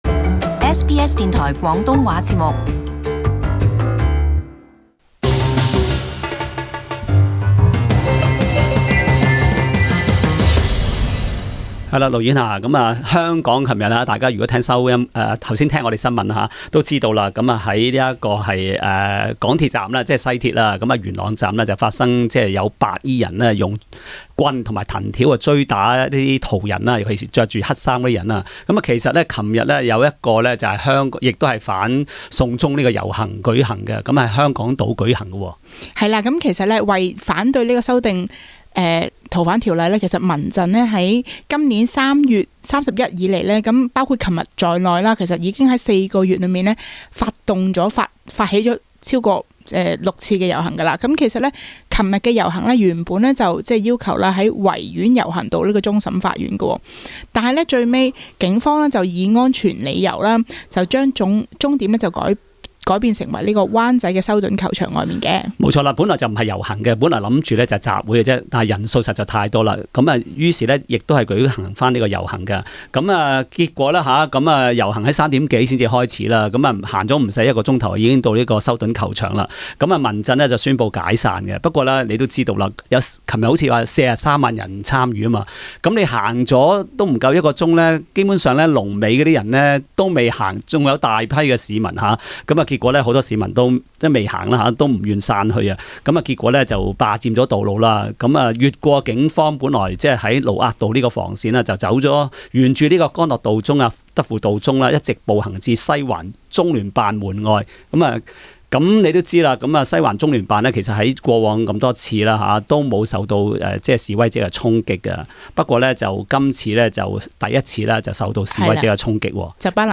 Source: AAP SBS廣東話節目 View Podcast Series Follow and Subscribe Apple Podcasts YouTube Spotify Download (11.49MB) Download the SBS Audio app Available on iOS and Android 昨日，香港民間人權陣線（民陣）再在港島舉辦遊行，促請政府成立獨立調查委員會。